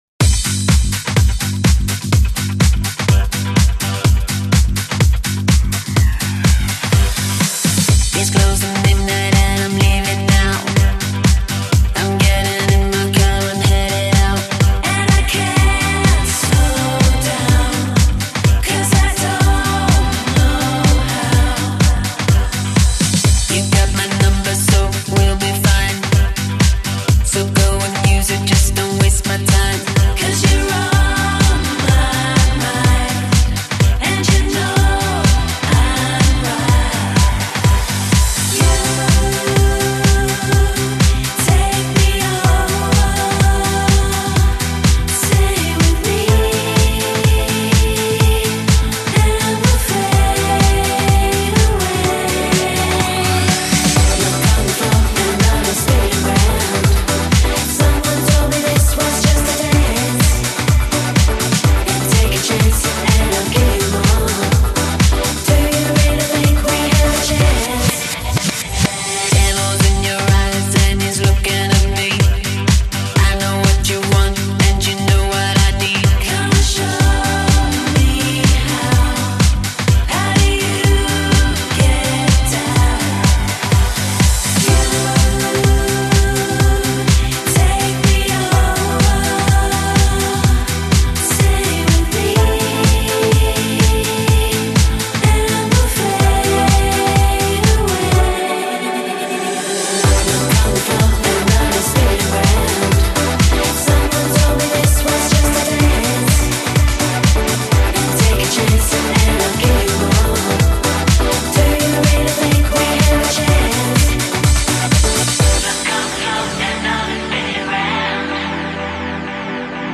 легкий хаус